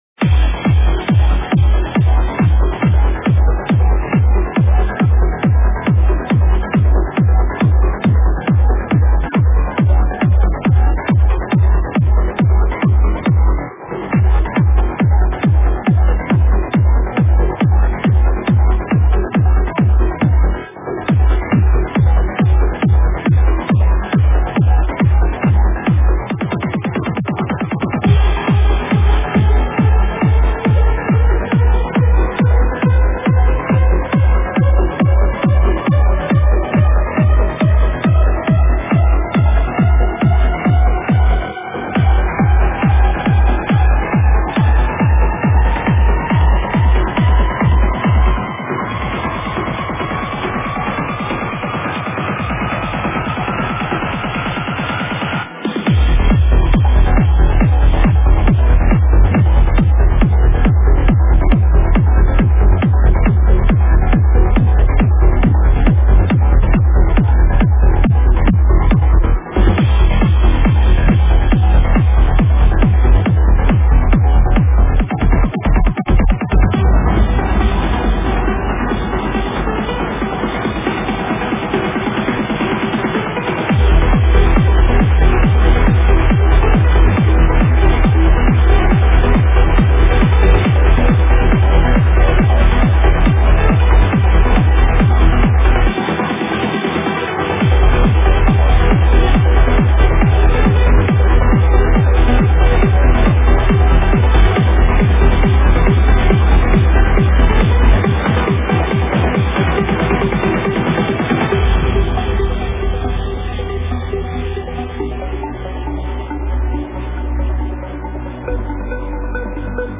Стиль: Trance